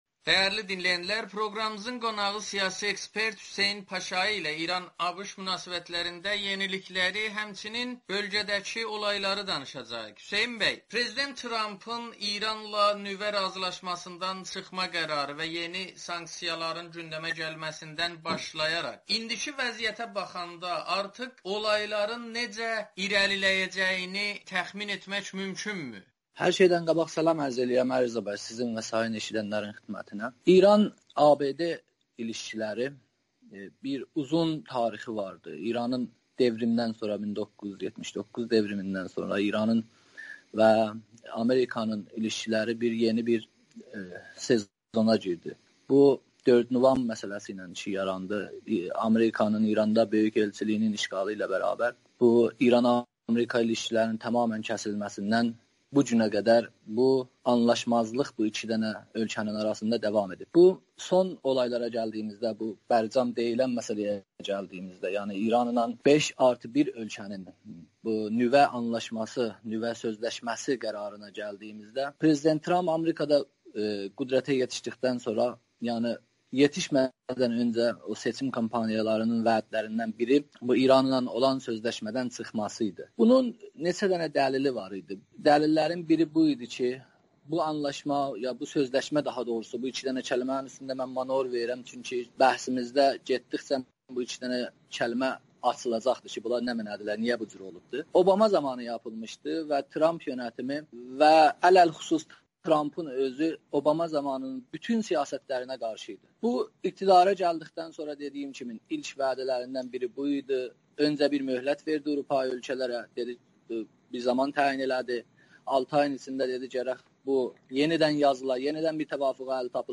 Siyasi ekspert Amerikanın Səsi ilə söhbətdə İran-ABŞ münasibətlərinin tarixini dəyərləndirməklə yanaşı hazırkı vəziyyət və İranın bölgədəki nüfuzu haqqında danışıb.